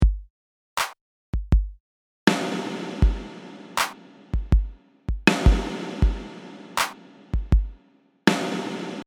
Here’s what we have after we’ve inserted our reverb:
As you can hear, that’s one long reverb tail.
reverbsnare.mp3